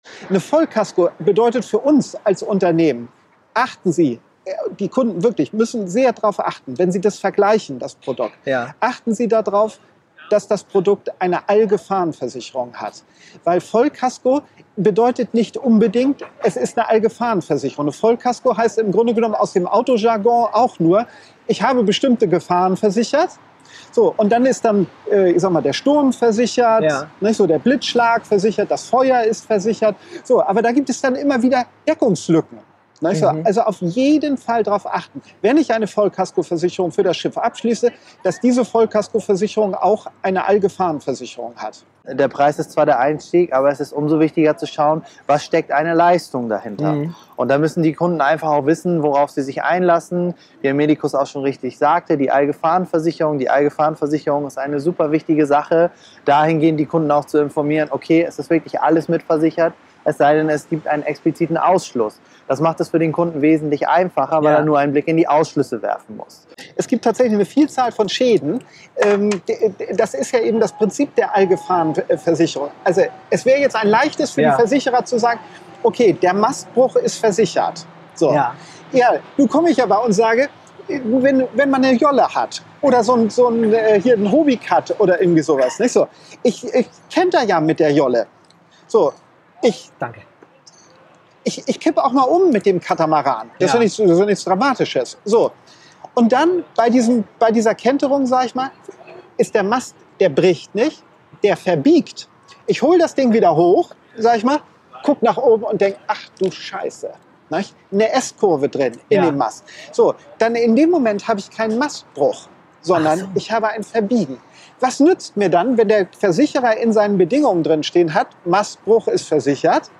Thema Allgefahrendeckung (Interviewausschnitt)
im Gespräch an den Hamburger Landungsbrücken